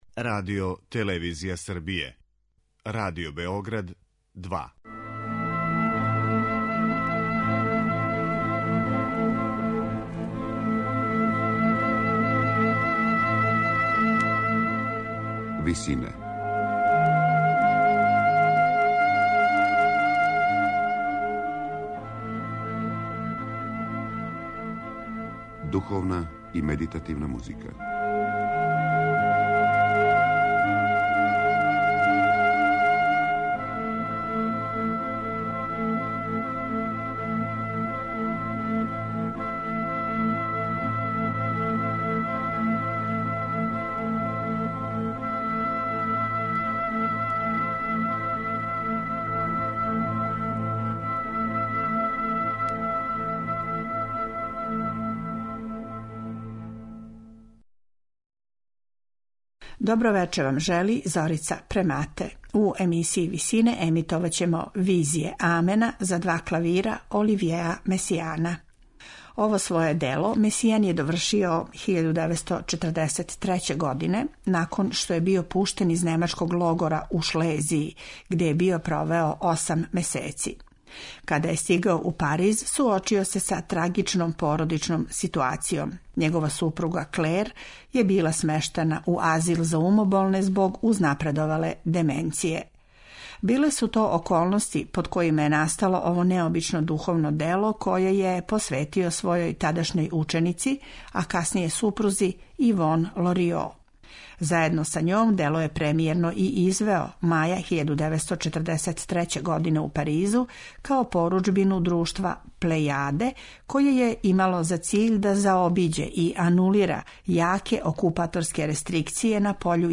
за два клавира